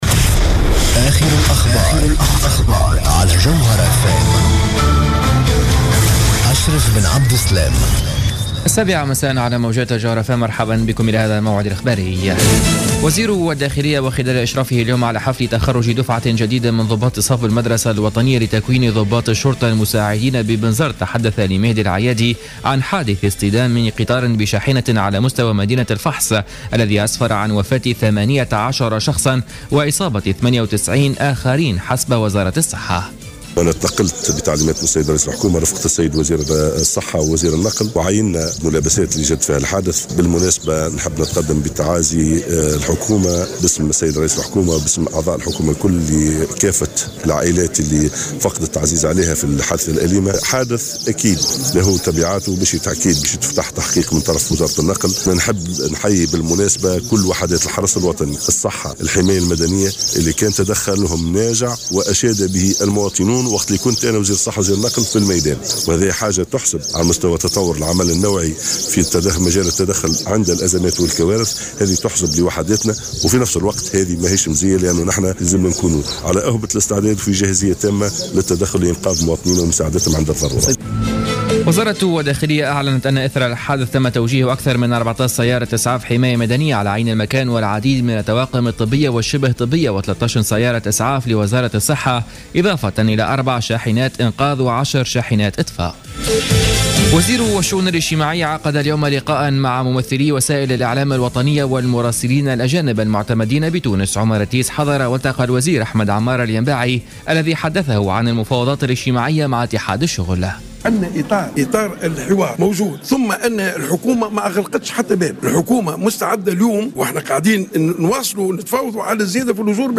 نشرة أخبار السابعة مساء ليوم الثلاثاء 16 جوان 2015